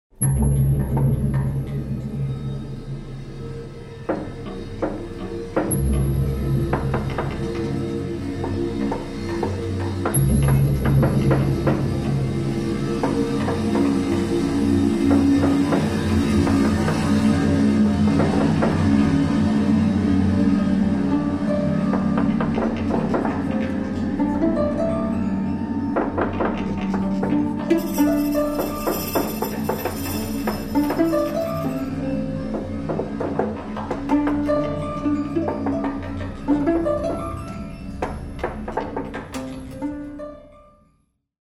for piano trio, fixed tape, and live electronics